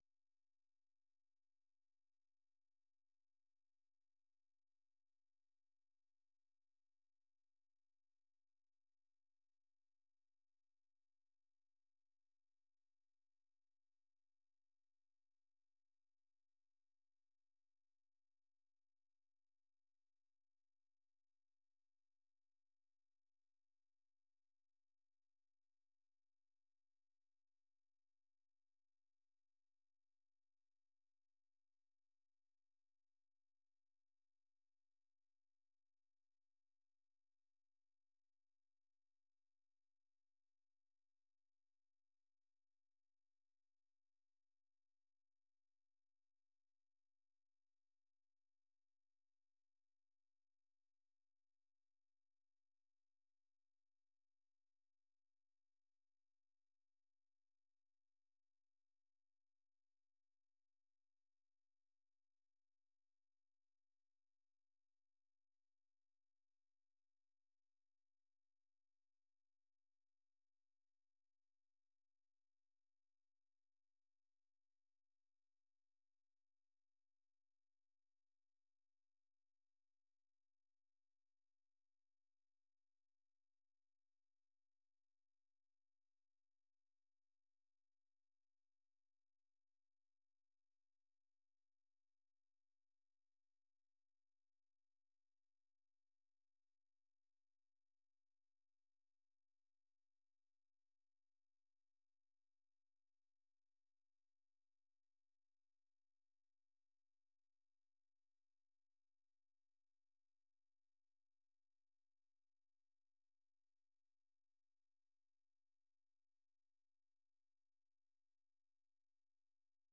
VOA 한국어 방송의 월요일 오전 프로그램 1부입니다.